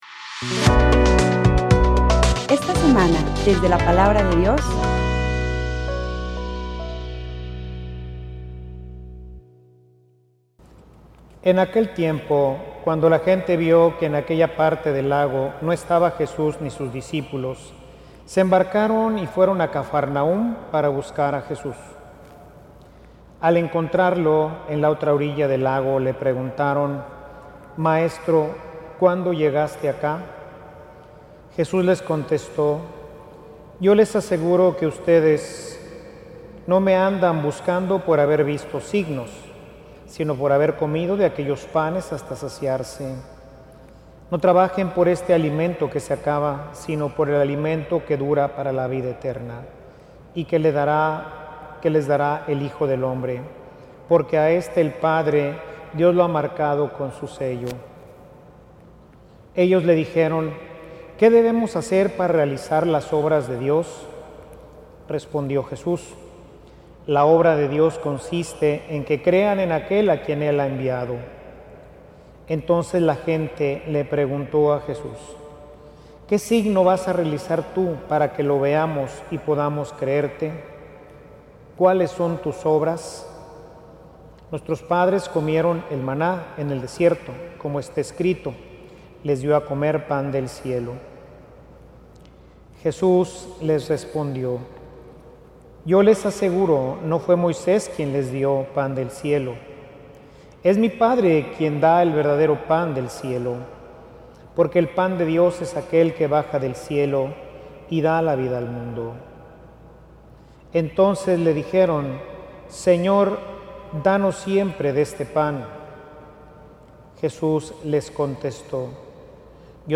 Homilia_En_donde_esta_puesta_nuestra_fe.mp3